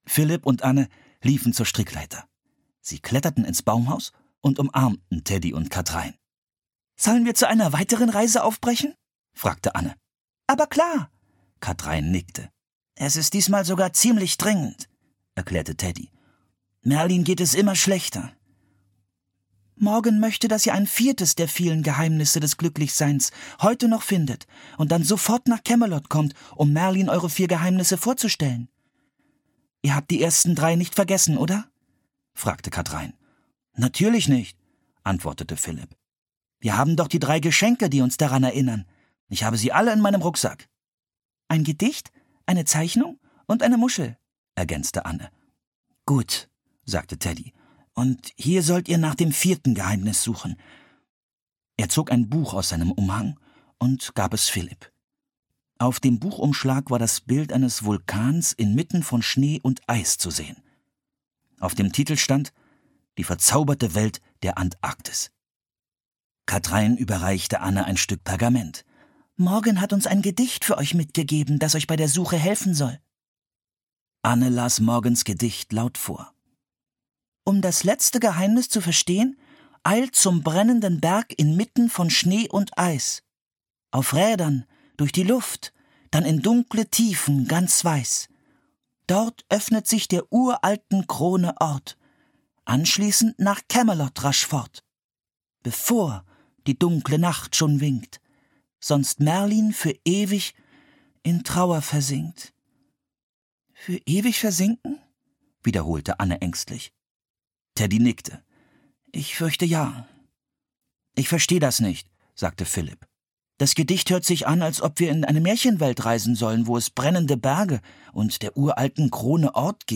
Das verborgene Reich der Pinguine (Das magische Baumhaus 38) - Mary Pope Osborne - Hörbuch